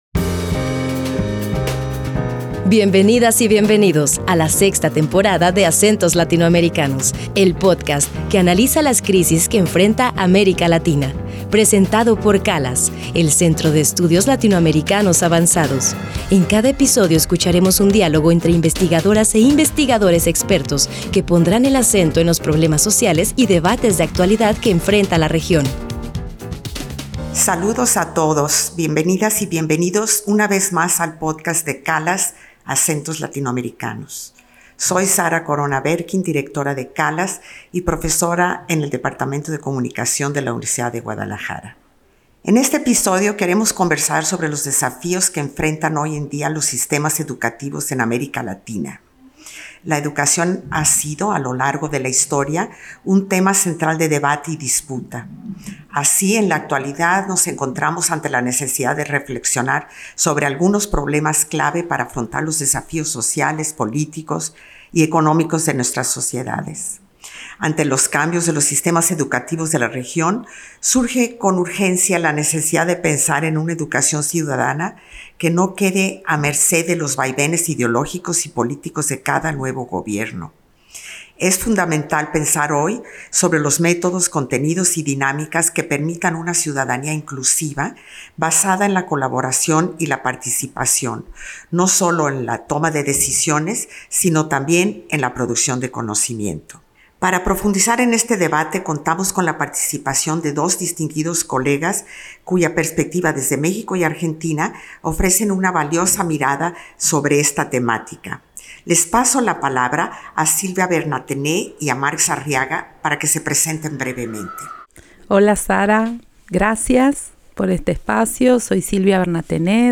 Un diálogo fundamental para comprender los desafíos del presente y proyectar los horizontes de la educación en América Latina.